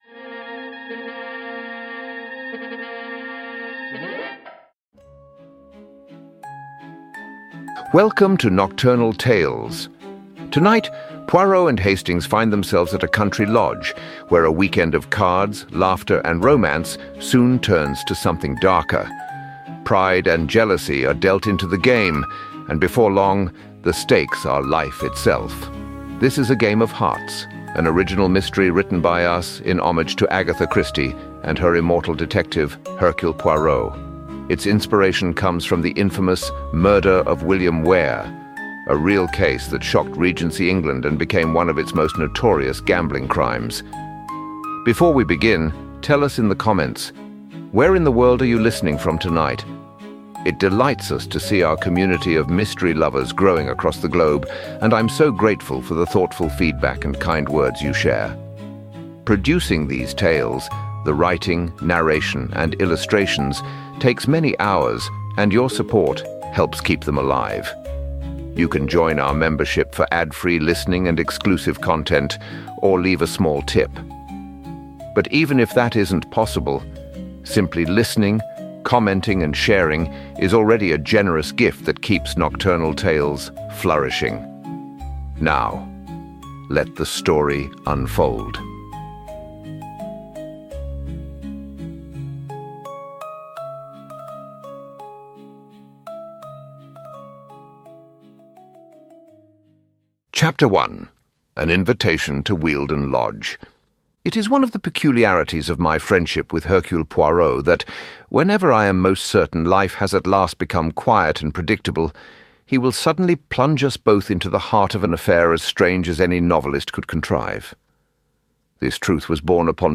Libros Narrados